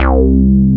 HOUSBAS3.wav